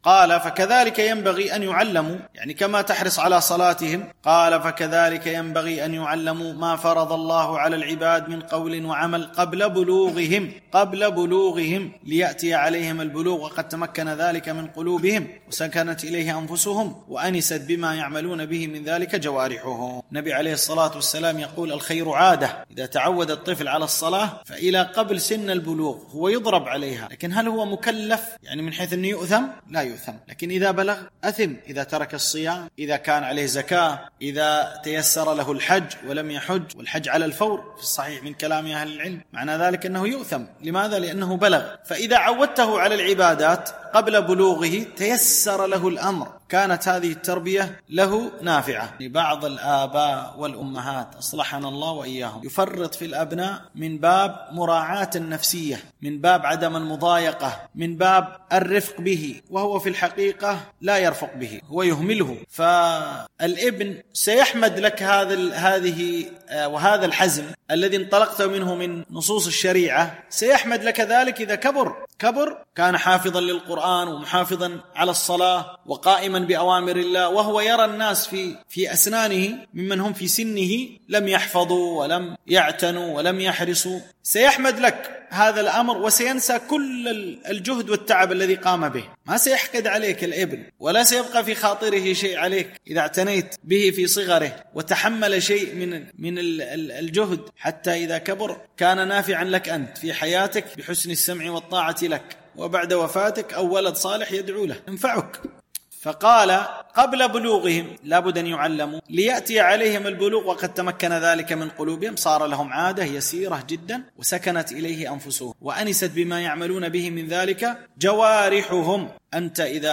التنسيق: MP3 Mono 44kHz 94Kbps (VBR)